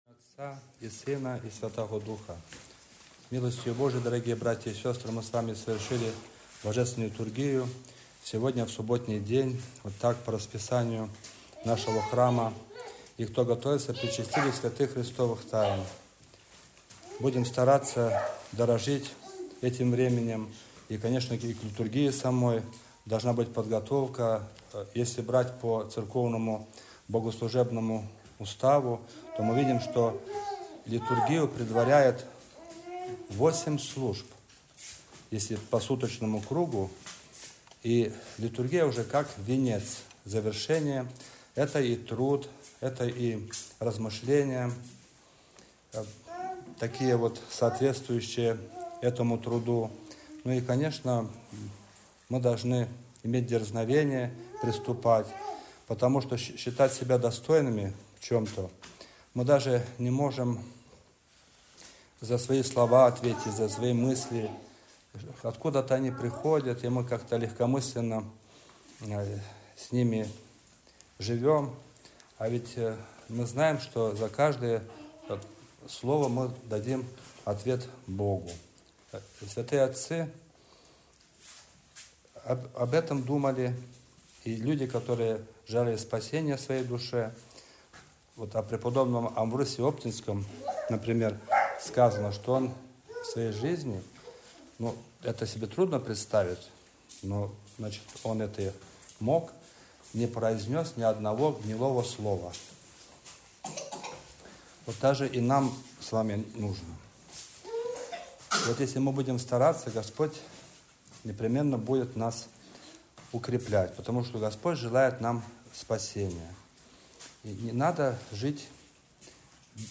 Проповедь.mp3